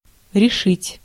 Ääntäminen
Tuntematon aksentti: IPA: /rʲɪˈʂɨtʲ/